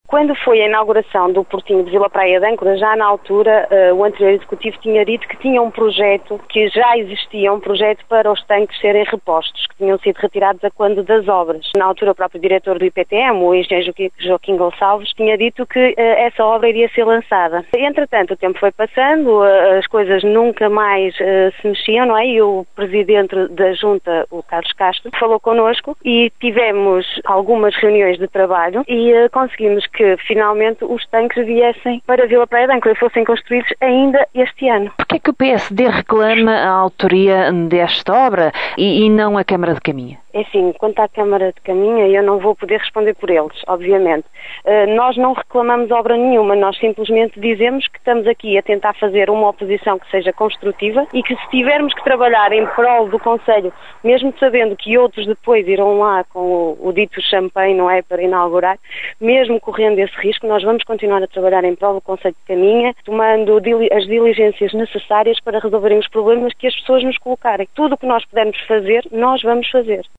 Em declarações à Rádio Caminha